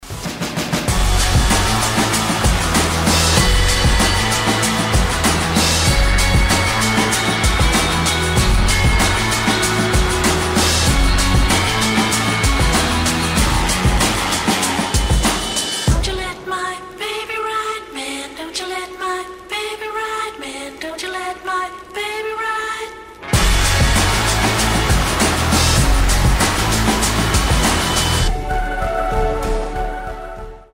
Soft rock
indie rock
garage rock
Бодрый саундтрек